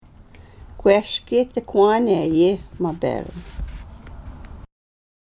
Reading Indigenous Translations of Riel: Heart of the North -- Audio Recordings